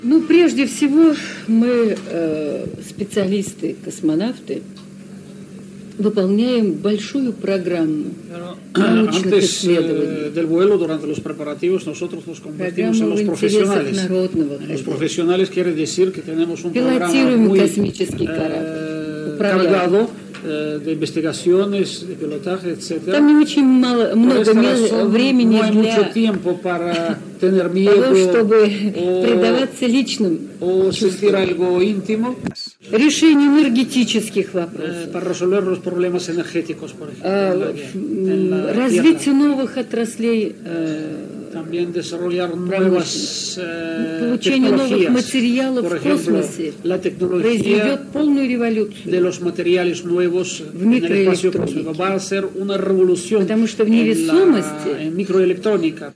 Declaracions de la cosmonauta rusa Valentina Tereshkova quan va visitar Madrid.
Entreteniment